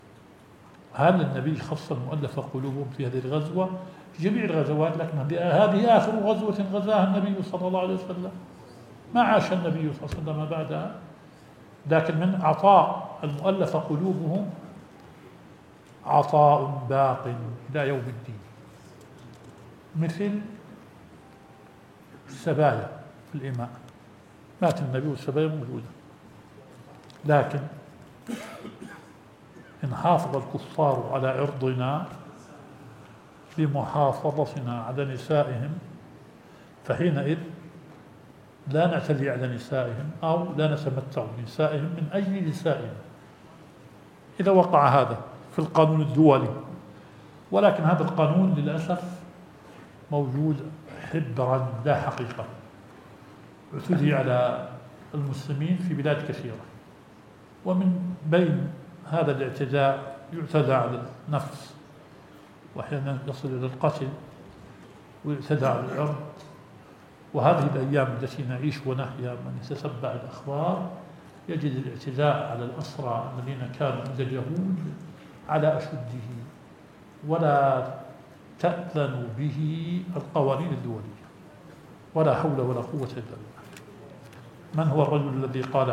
البث المباشر